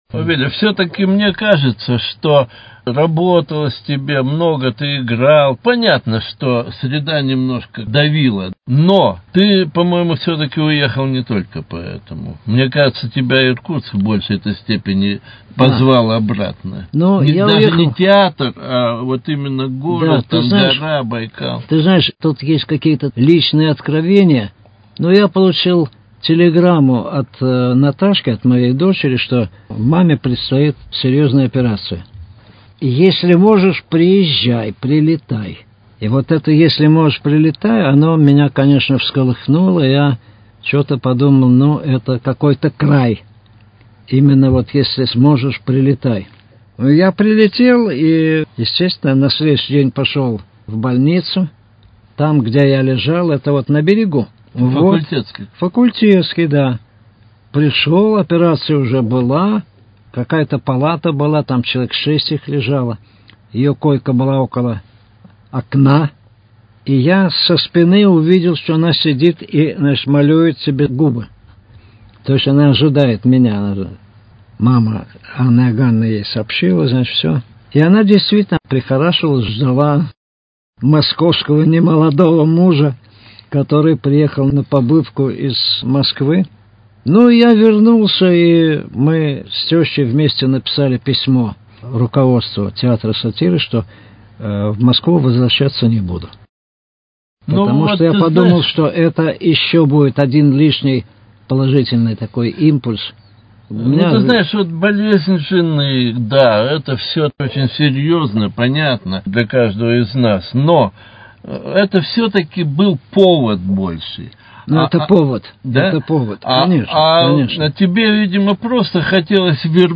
С Иркутском связанные судьбы: Беседа с народным артистом РСФСР Виталием Венгером.